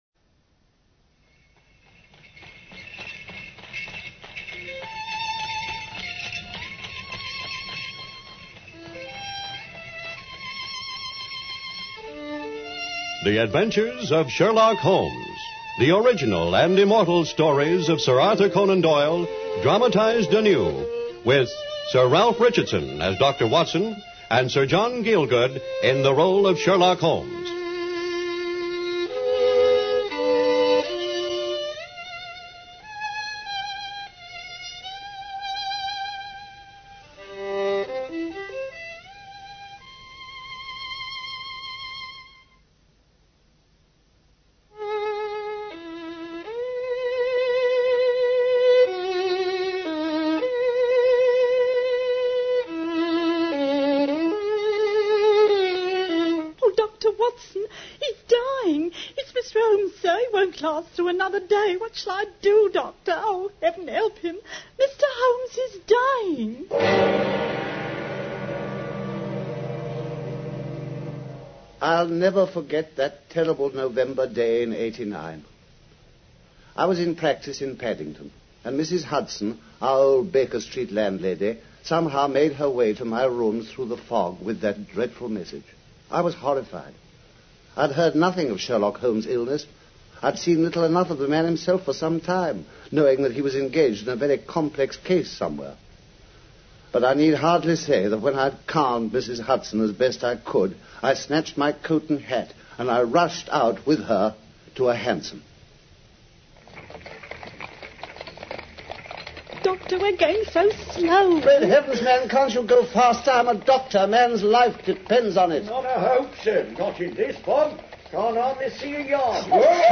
Radio Show Drama with Sherlock Holmes - The Dying Detective 1954